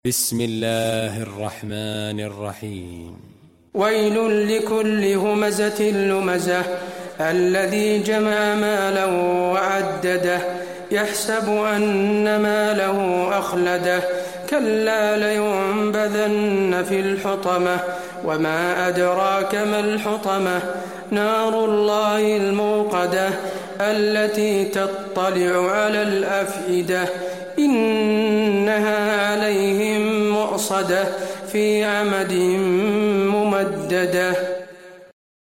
المكان: المسجد النبوي الهمزة